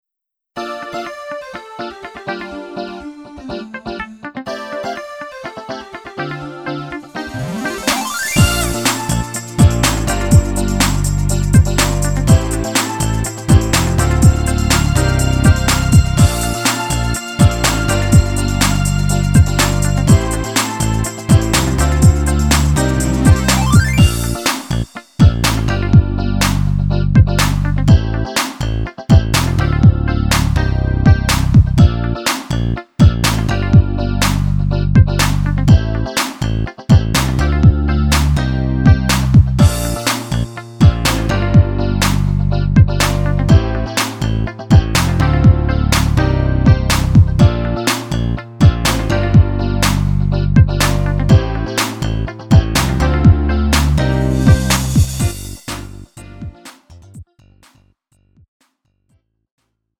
음정 원키 3:39
장르 가요 구분 Lite MR